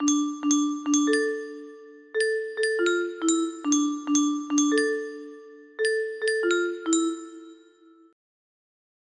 Max music box melody